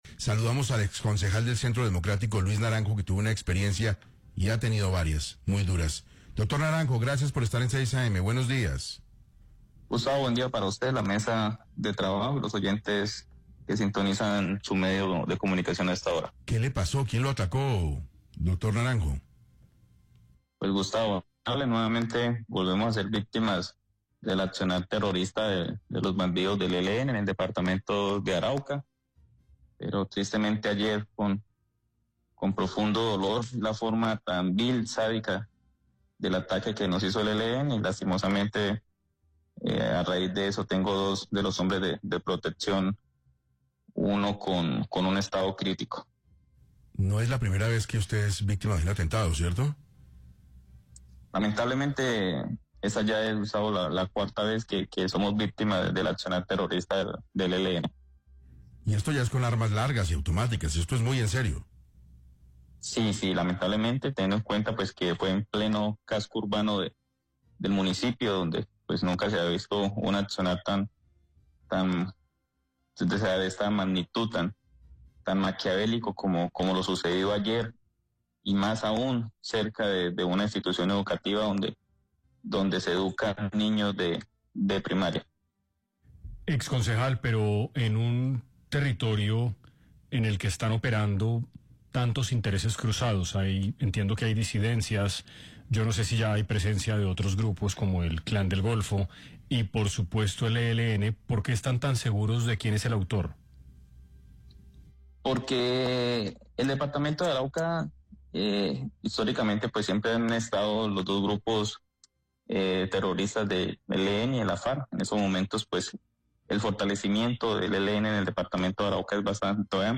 El exconcejal del Centro Democrático, Luis Naranjo, denunció en entrevista con 6AM Caracol Radio que fue víctima de un nuevo atentado en el departamento de Arauca.
Durante la entrevista, Naranjo explicó que el ELN no solo ha fortalecido su presencia militar en la región, sino también su influencia política, social y económica.